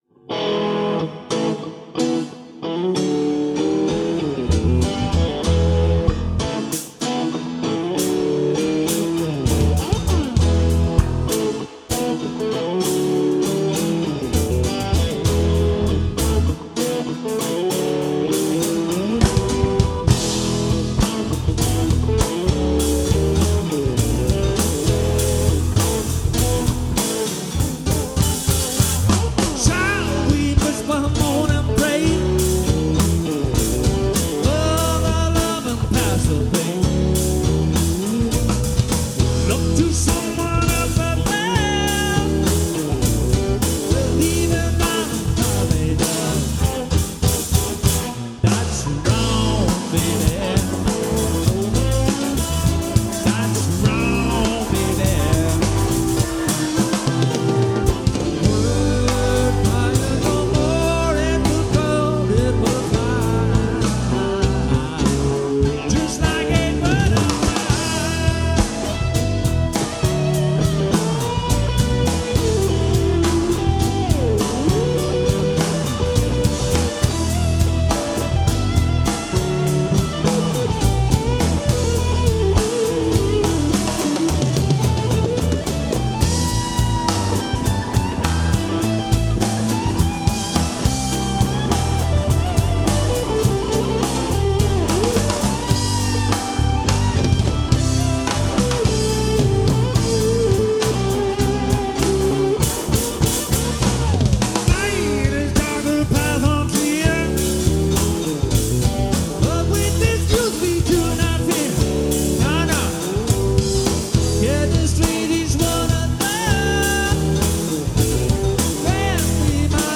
– Rockmusik –
Gekürzte Live-Mitschnitte
(ab Mixer-Ausgang)